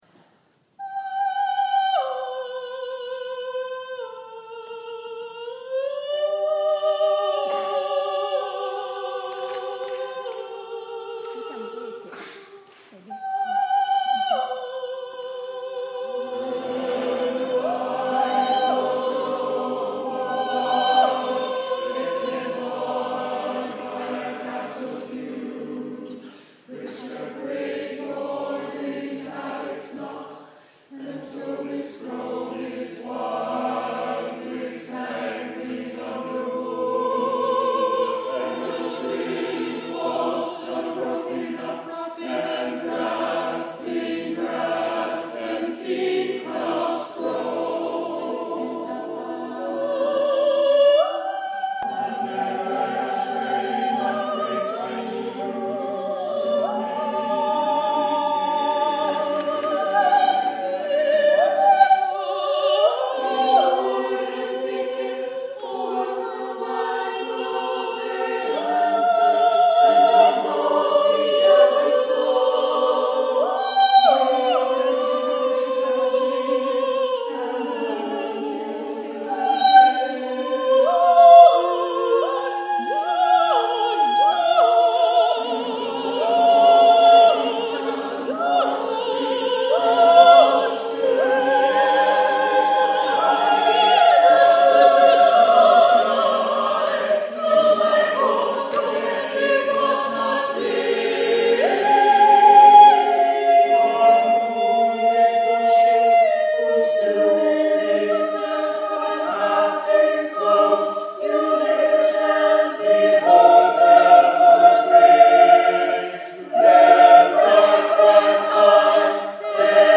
SATB Choir
nightingale-rehearsal.mp3